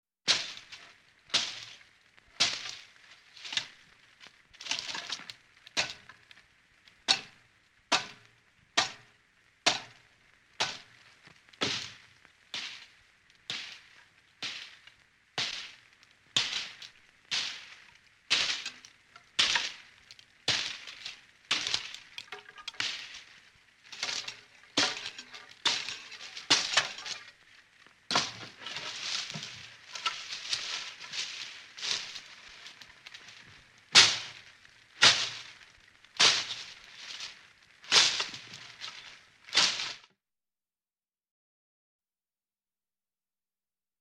Нож Боло: нарезка
Тут вы можете прослушать онлайн и скачать бесплатно аудио запись из категории «Холодное оружие».